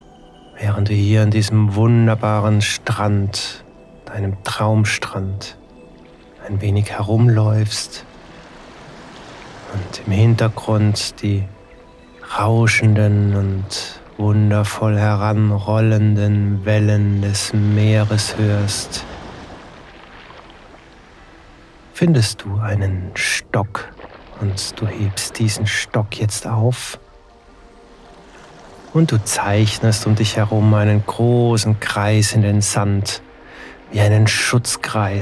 Audio-Hypnosen
mit ausgewählter und stimmungsvoller Musik untermalt